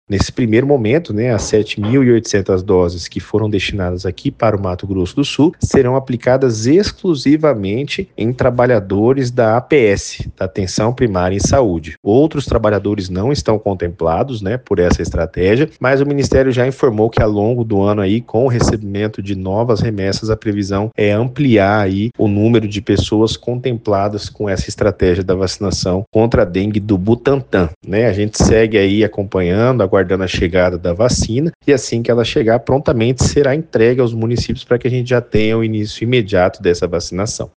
Em entrevista à FM Educativa MS 104.7